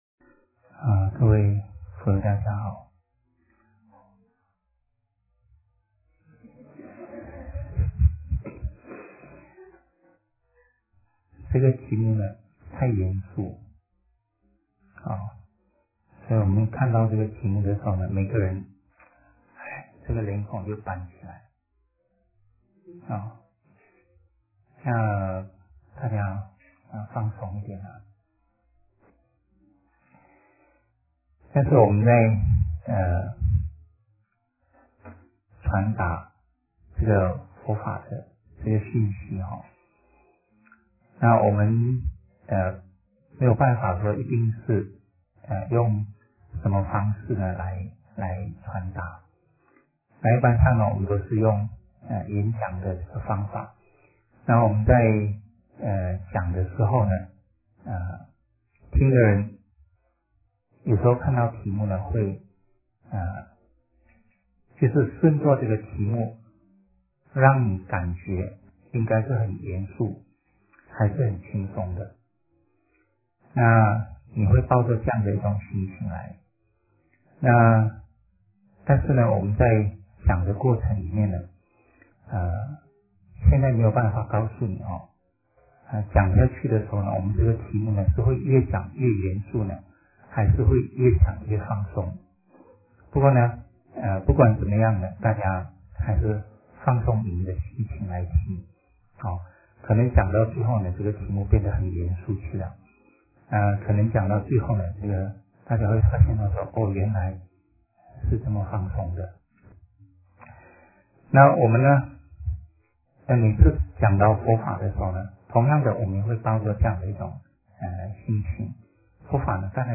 A Public Lecture